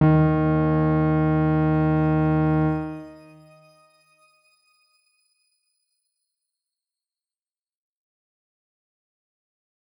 X_Grain-D#2-pp.wav